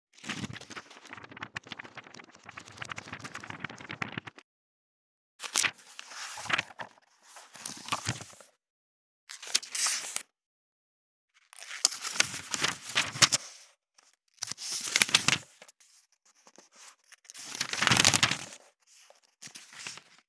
5.本ページめくり【無料効果音】
ASMR効果音本をめくる
ASMR